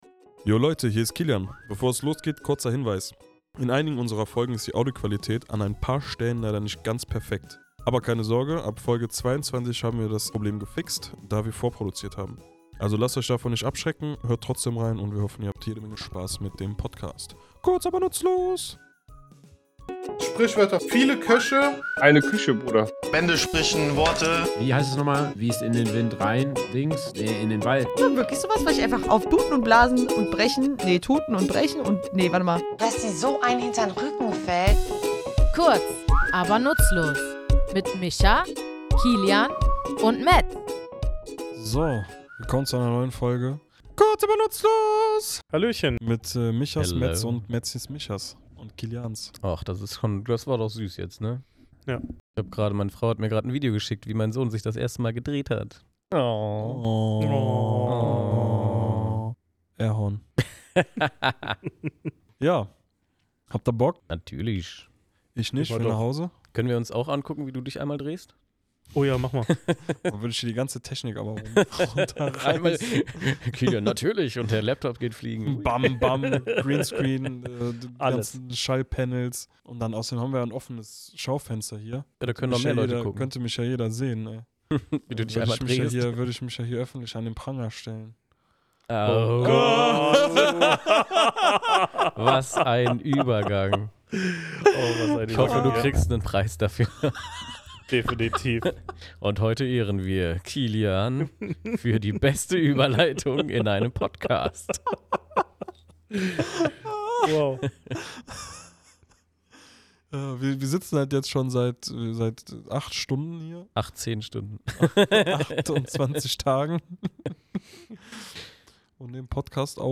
Wir, drei tätowierende Sprachliebhaber, tauchen in unserem Tattoostudio in die dunkle Geschichte und die heutige Bedeutung dieser Redensart ein.